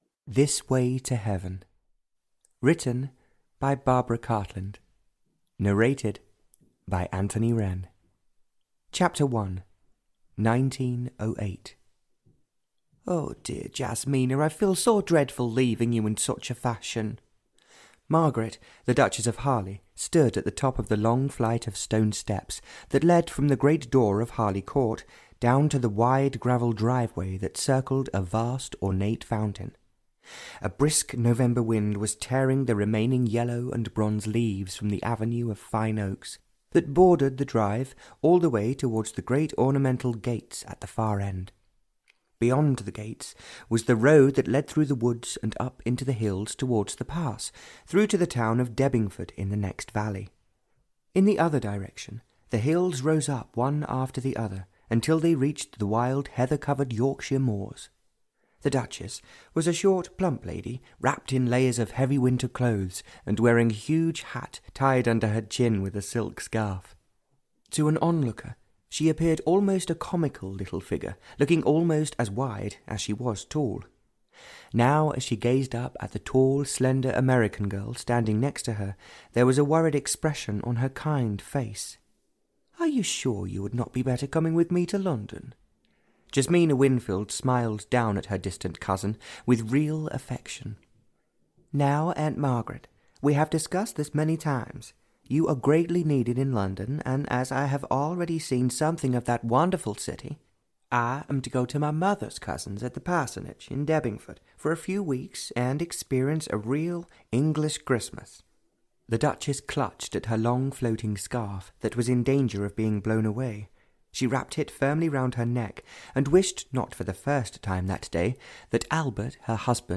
This Way to Heaven (Barbara Cartland’s Pink Collection 50) (EN) audiokniha
Ukázka z knihy